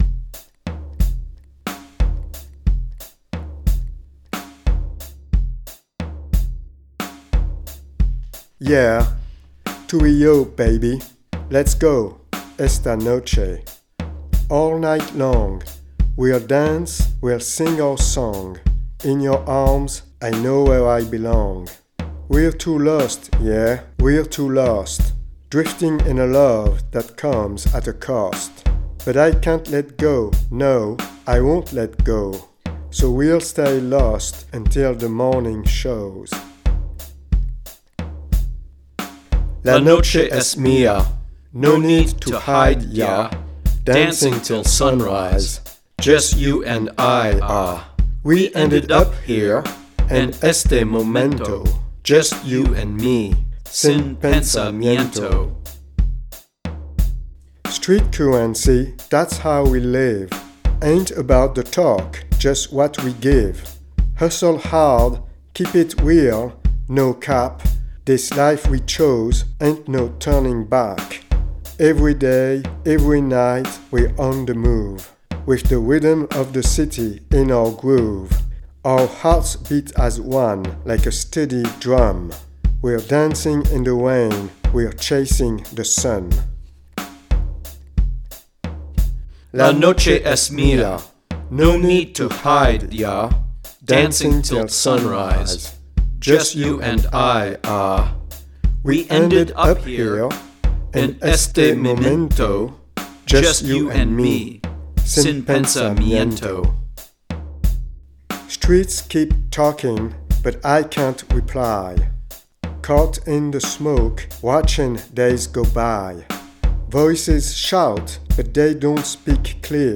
drum machine
Chorus vocals on tracks 1